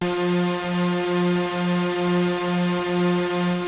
KORG F4  3.wav